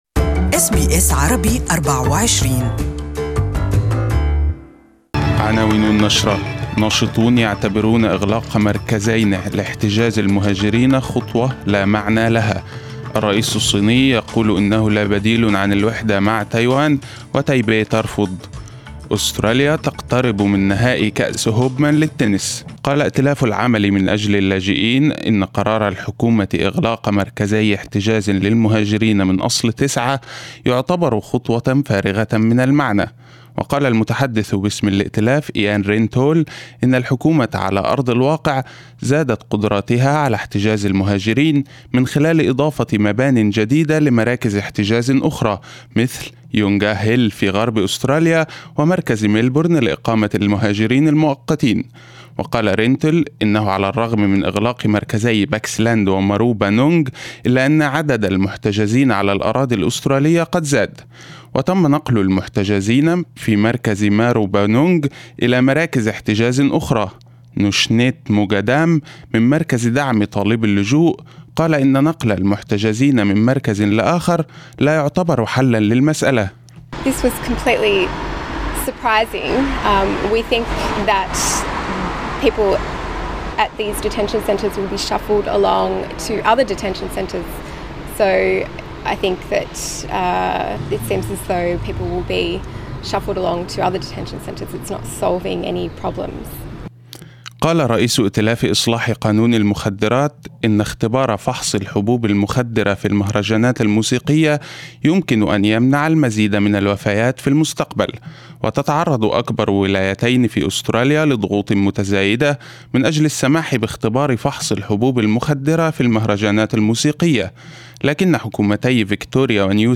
Morning news bulletin in Arabic.